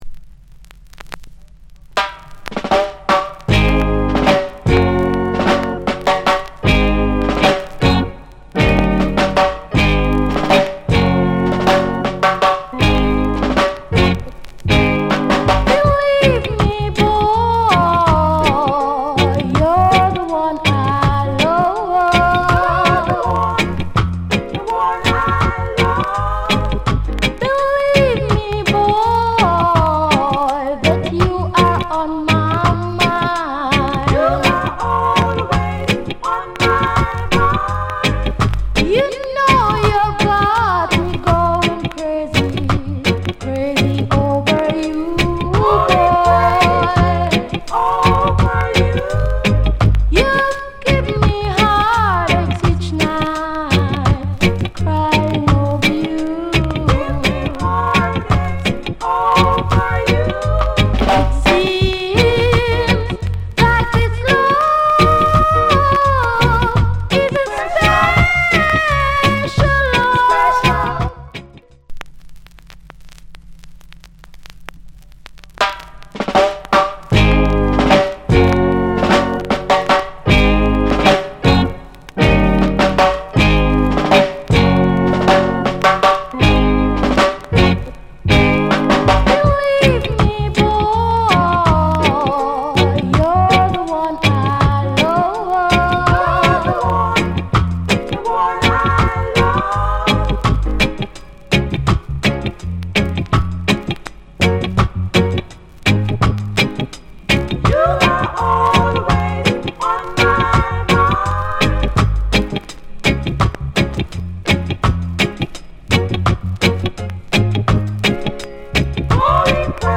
Lovers Rock!!